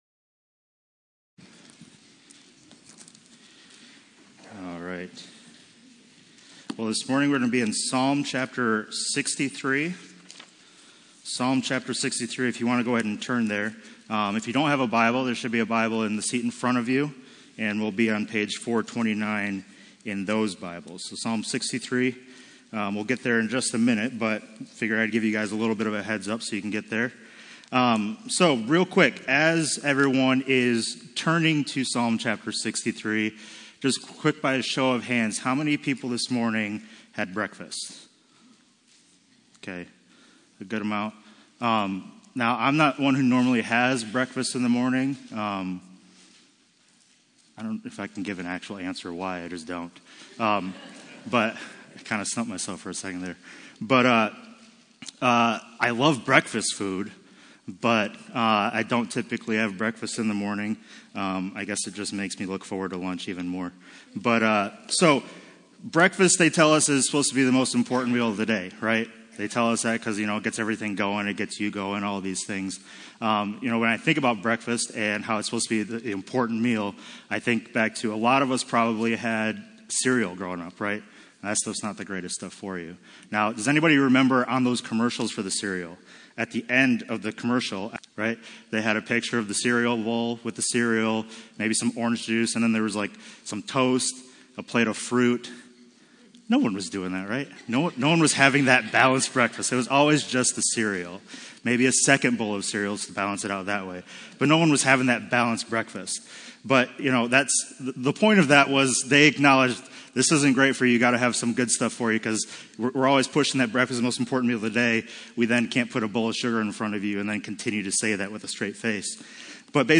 Passage: Psalm 63 Service Type: Sunday Morning CCLI Streaming Plus License